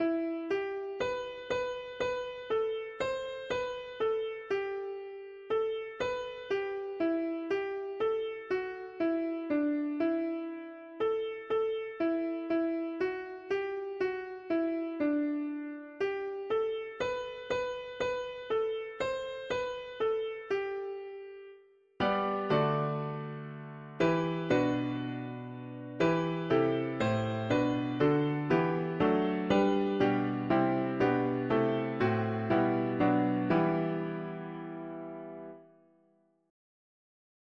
Immanuel sh… english christian winter 4part